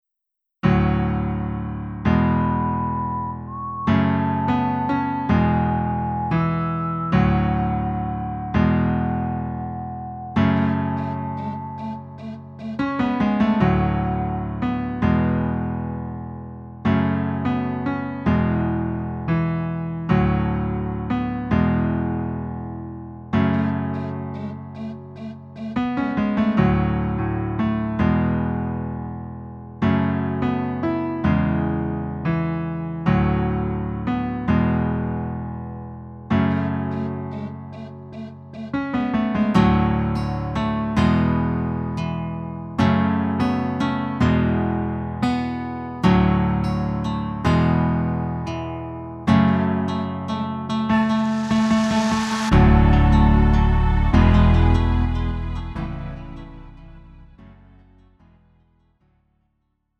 음정 -1키 3:13
장르 가요 구분 Lite MR